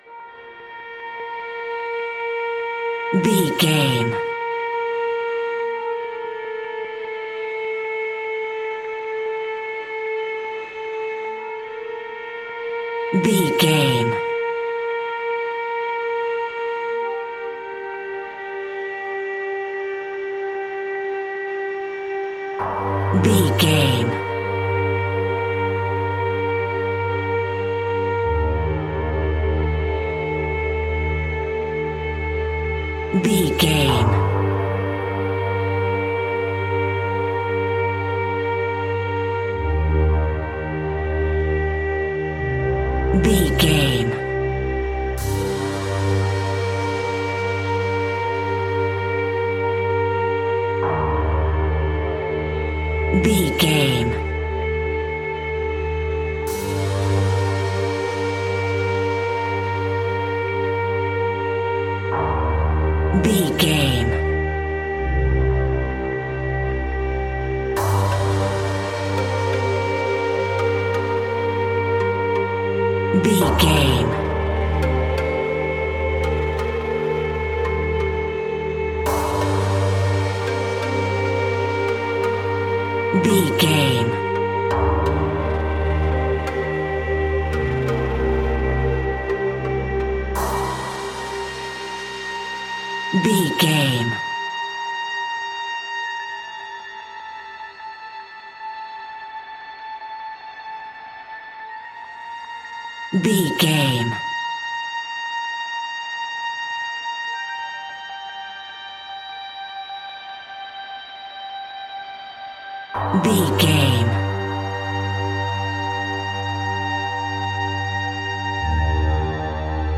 Aeolian/Minor
scary
ominous
dark
eerie
synthesizer
drum machine
ticking
electronic music
Horror Synths